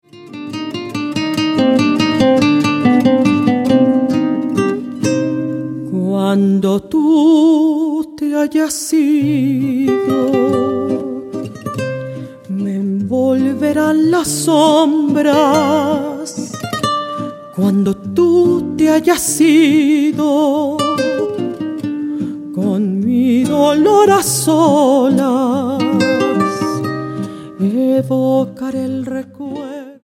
mezzosoprano
guitarras